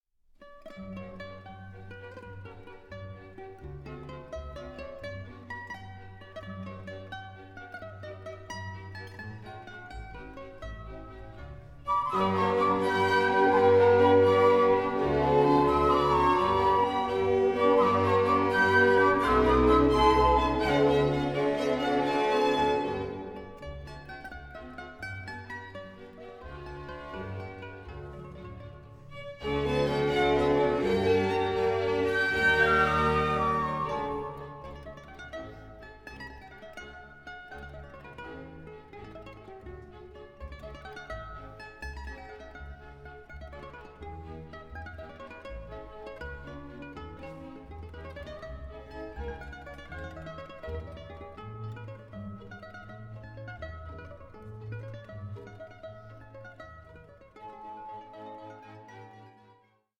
Larghetto and Rondo for Mandolin and Orchestra